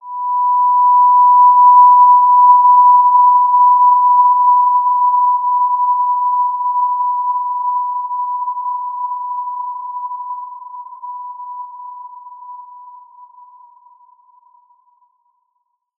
Basic-Tone-B5-mf.wav